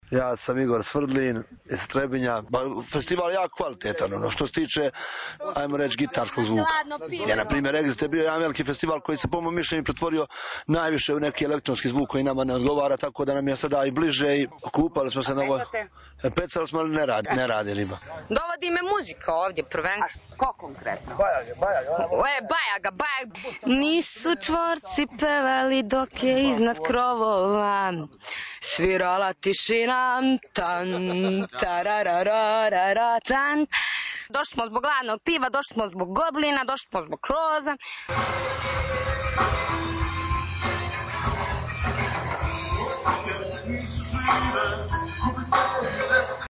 Dio festivaske publike boravio je u Lejk kampu, u hladu krupačkih topola.
Posjetioci o Lejk festu